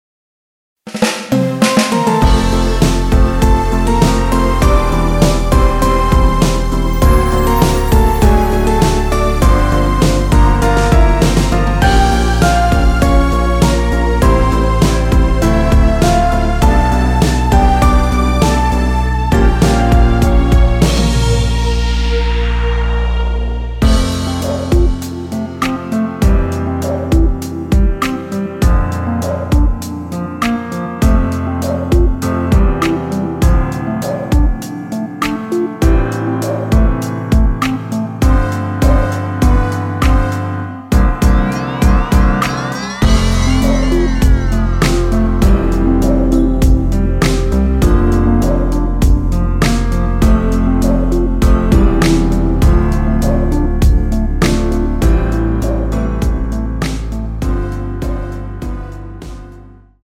원키에서(-6)내린 MR입니다.
앞부분30초, 뒷부분30초씩 편집해서 올려 드리고 있습니다.